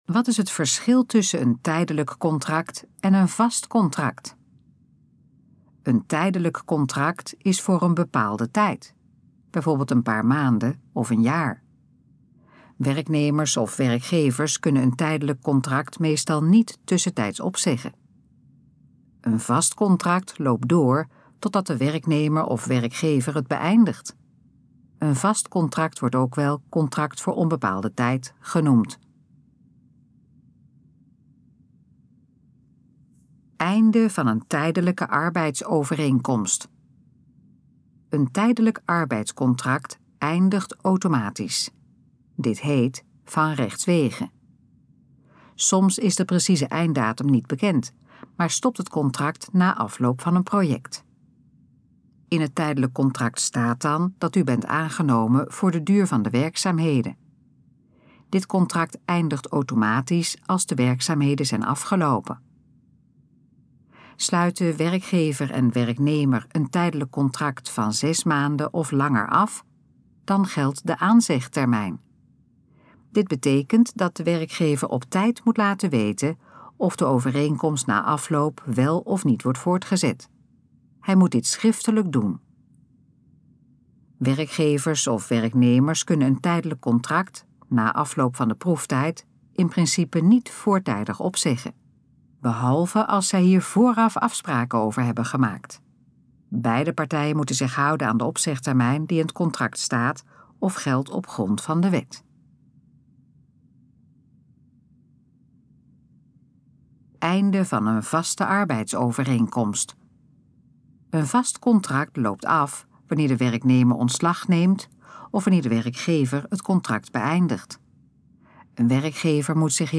Gesproken versie van Wat is het verschil tussen een tijdelijk contract en een vast contract?
Dit geluidsfragment is de gesproken versie van de informatie op de pagina Wat is het verschil tussen een tijdelijk contract en een vast contract?